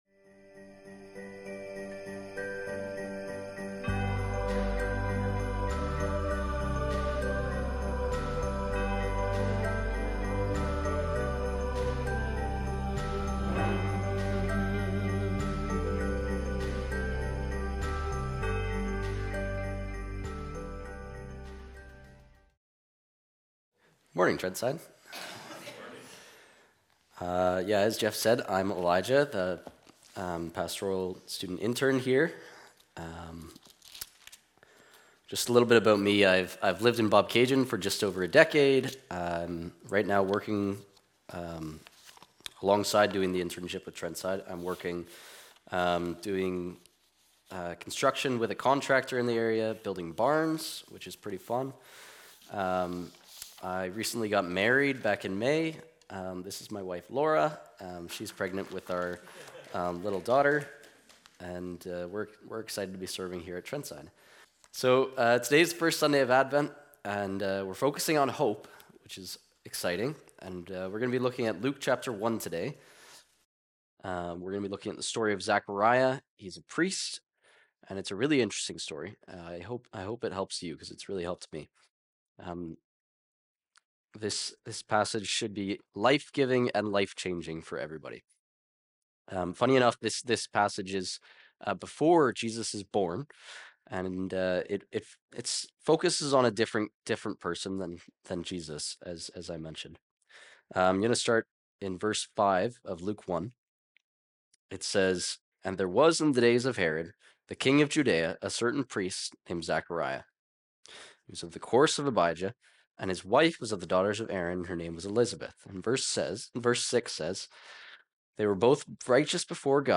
Recorded Sunday, November 30, 2025, at Trentside Bobcaygeon.